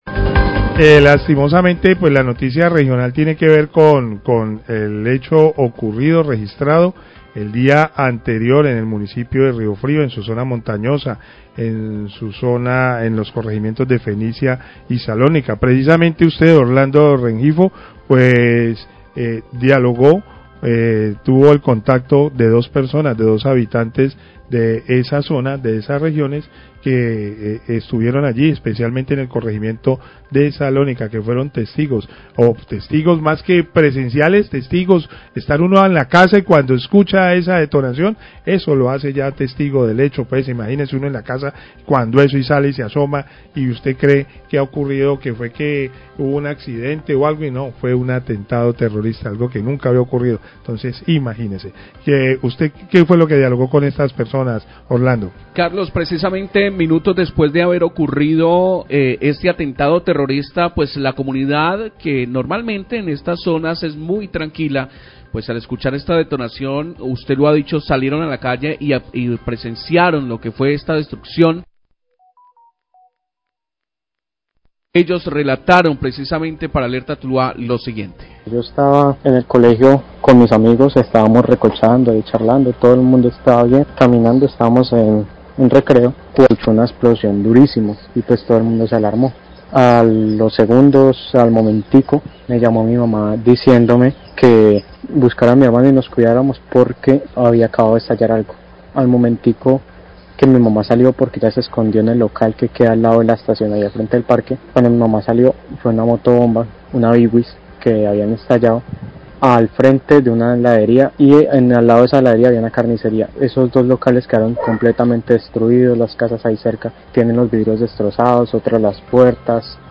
Radio
Testigos del atentado con explosivos en el corregimiento de Salónica, hablan de la ingrata experiencia que vivieron por efectos de la explosión de la motobomba. Menciona las afectaciones por la onda explosiva en viviendas, locales comerciales y las redes de energía.
(Son los mismo audios emitidos en la nota de ayer en la tarde peor hay más contextualización por parte de lso periodistas)